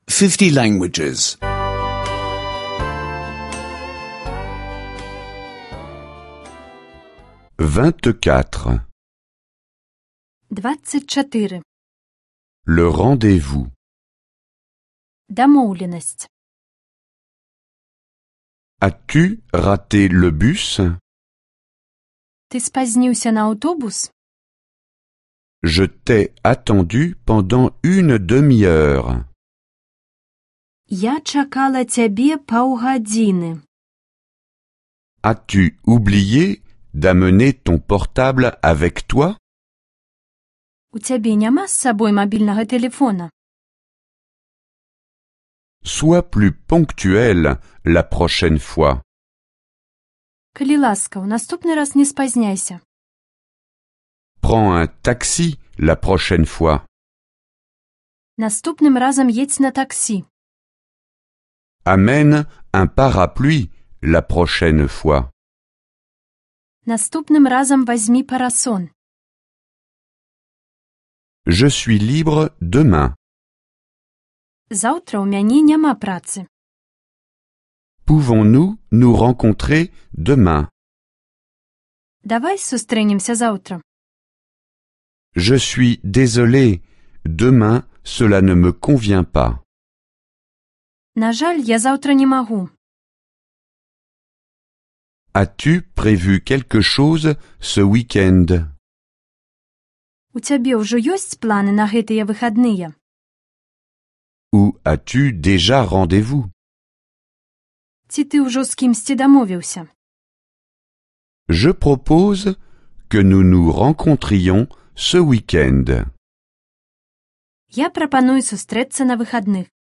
Cours audio de biélorusse (téléchargement gratuit)